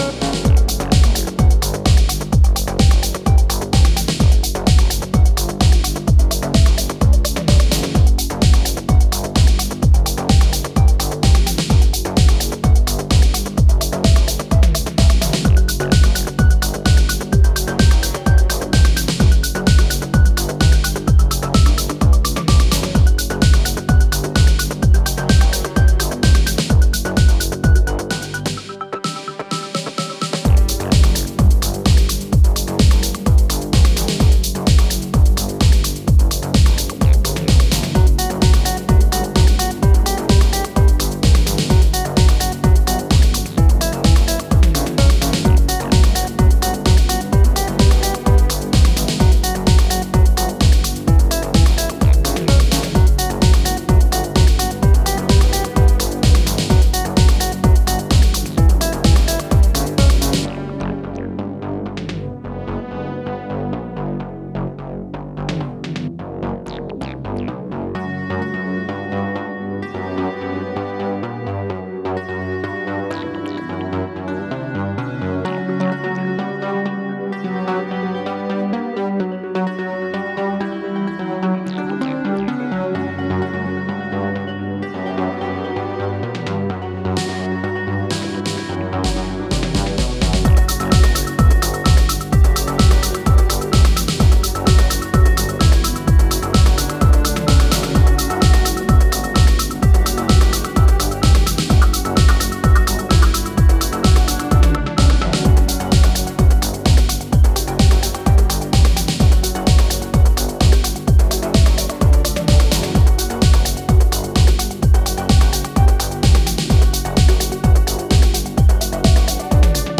hypnotic, driving techno / prog.